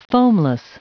Prononciation du mot foamless en anglais (fichier audio)
Prononciation du mot : foamless